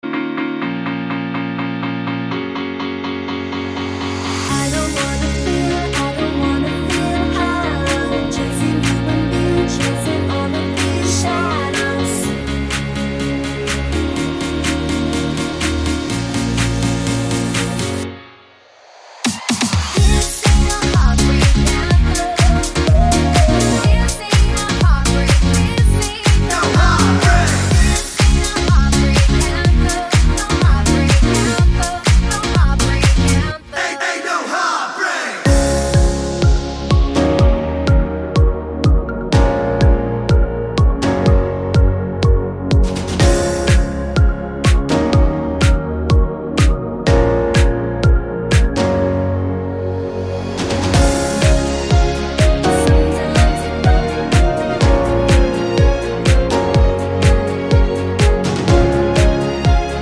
no Backing Vocals Pop